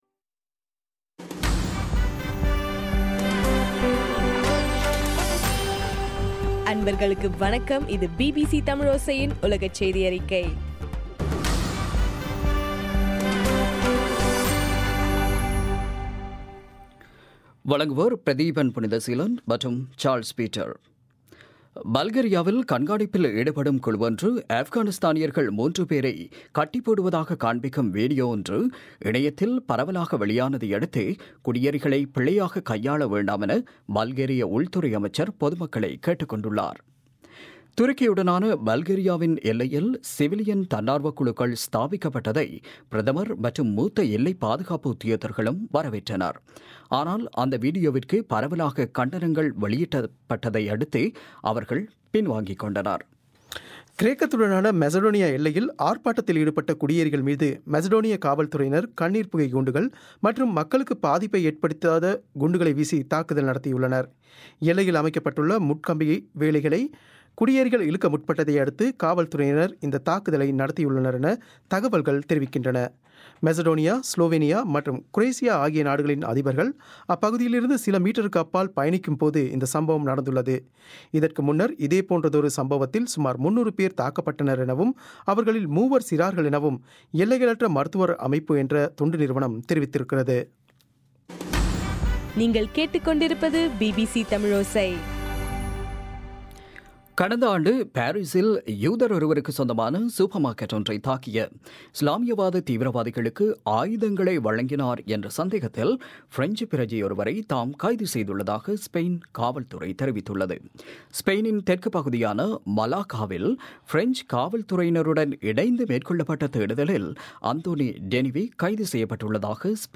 13 ஏப்ரல் 2016 பிபிசி செய்தியறிக்கை